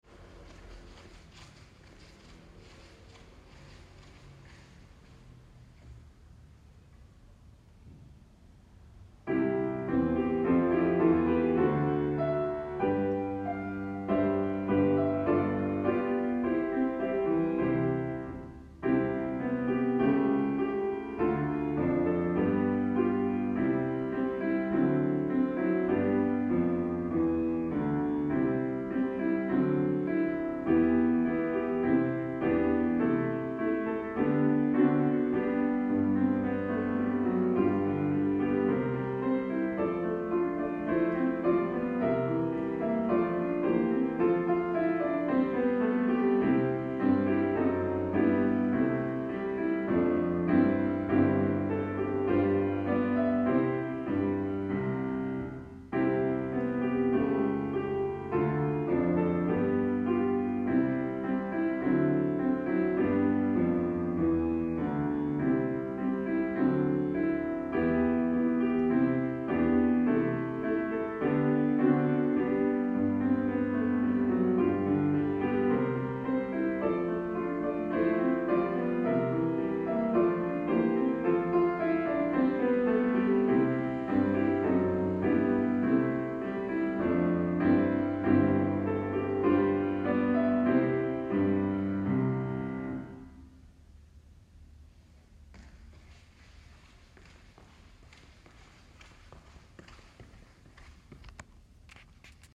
校歌
日吉台小学校校歌 ←クリックすると、主旋律をなぞった伴奏が流れます。